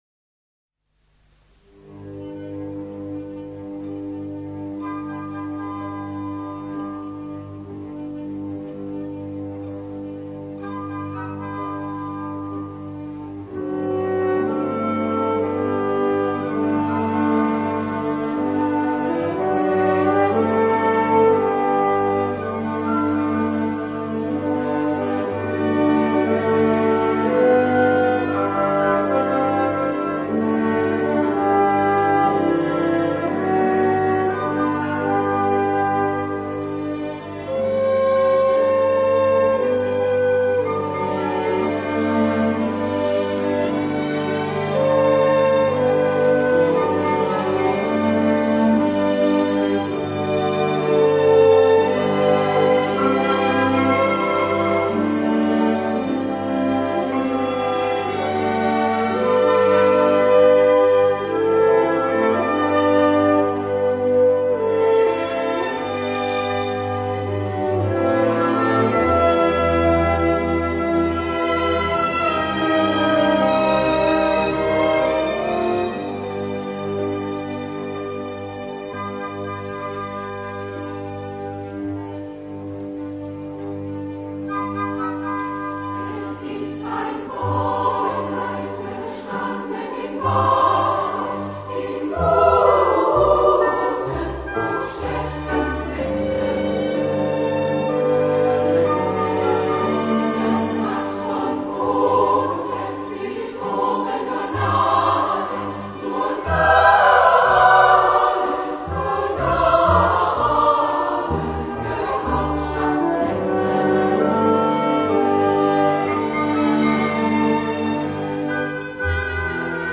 for women’s chorus and orchestra